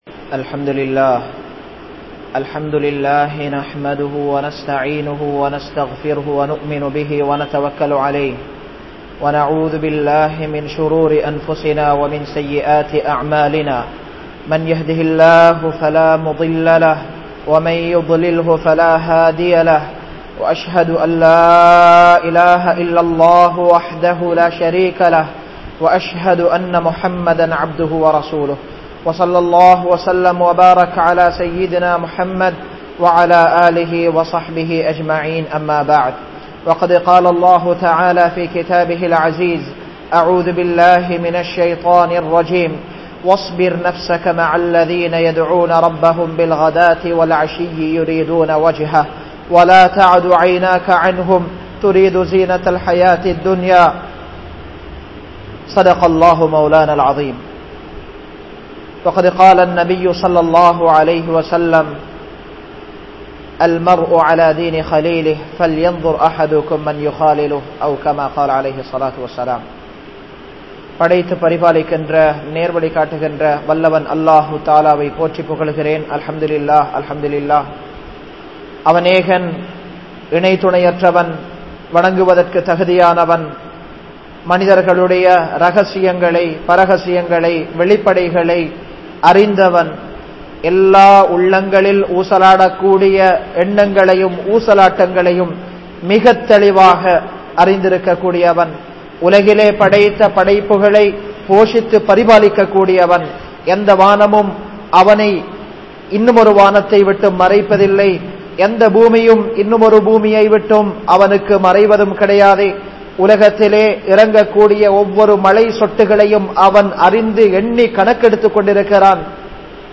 Vaalkaiyai Maattrum Nanparhal(வாழ்க்கையை மாற்றும் நண்பர்கள்) | Audio Bayans | All Ceylon Muslim Youth Community | Addalaichenai
Gorakana Jumuah Masjith